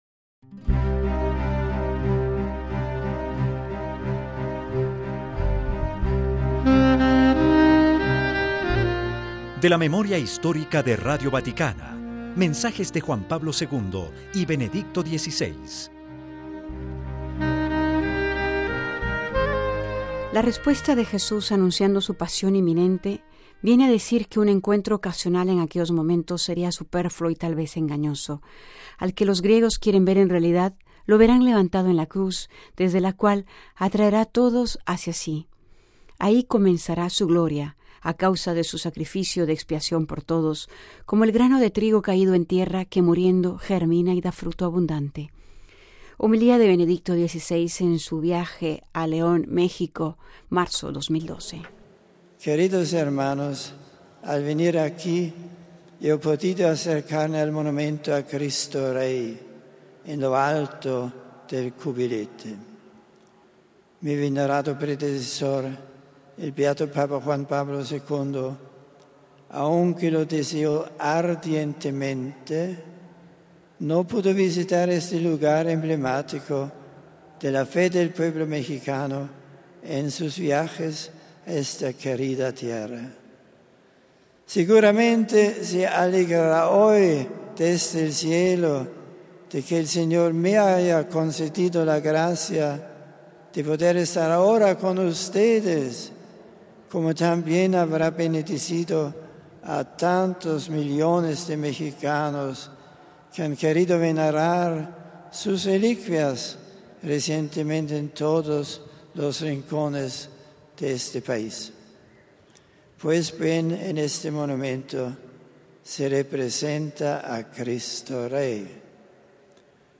Homilía de Benedicto XVI en el Parque Expo Bicentenario de León, 25 de marzo del 2012.